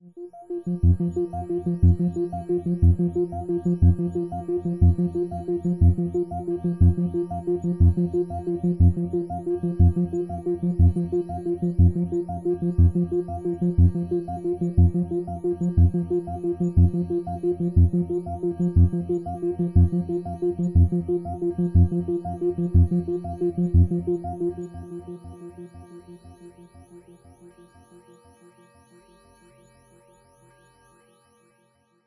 科学幻想的声音 " 警报声3
Tag: 机械 电子 机械 未来 航天器 外星人 空间 科幻 噪音